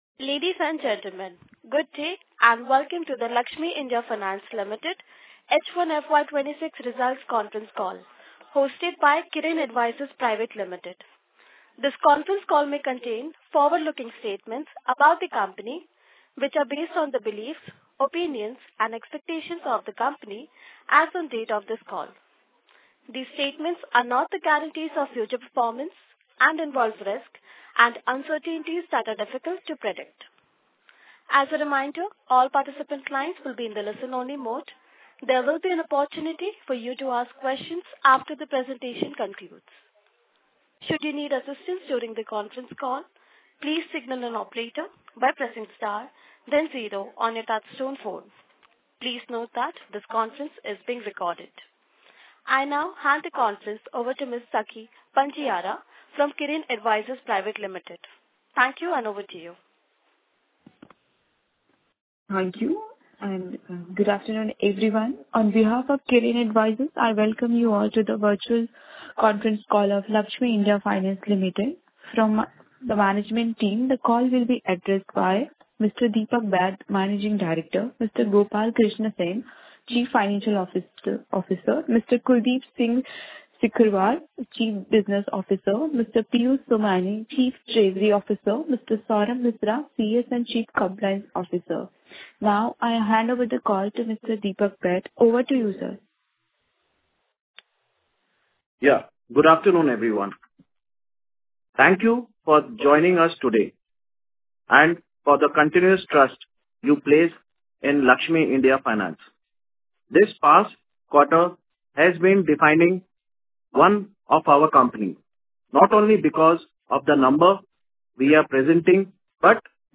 Q2-H1-FY26-Earnings-Call-Audio-Laxmi-India-Finance-Limited.mp3